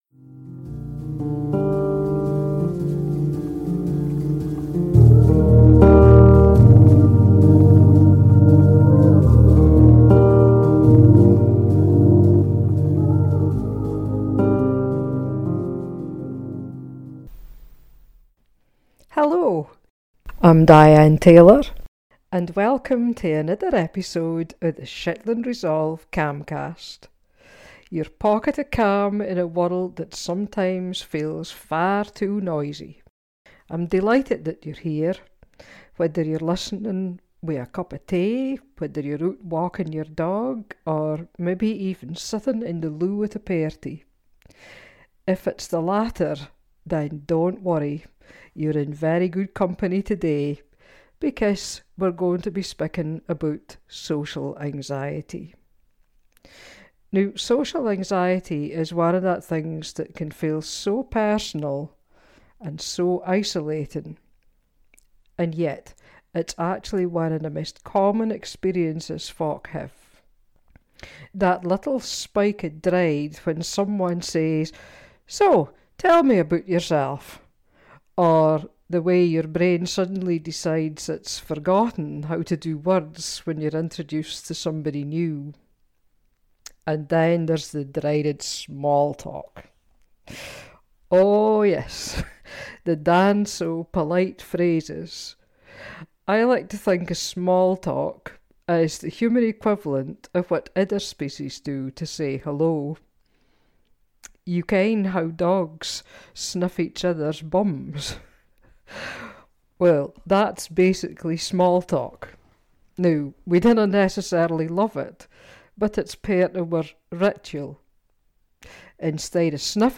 With warmth, humour, and down-to-earth Shetland storytelling, she explains how our brains act like faulty smoke alarms and why small talk is really just the human equivalent of dogs sniffing each other’s bottoms!